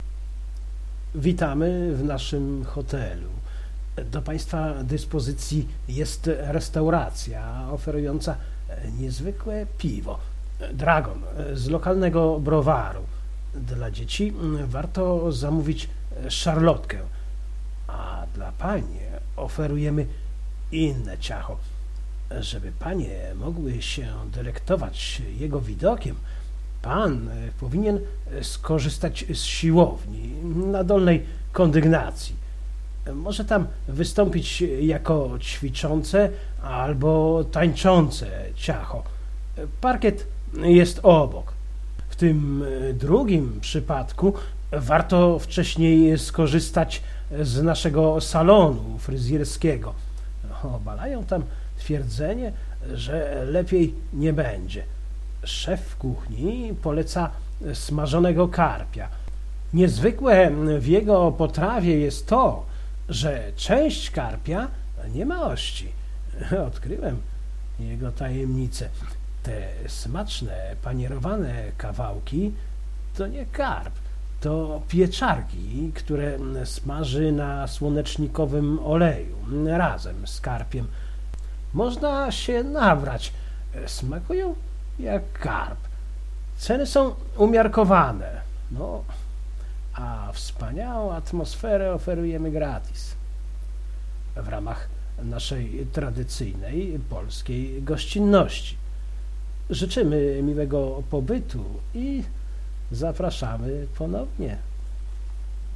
Tekst będzie w formie pliku MP3, czytany przeze mnie.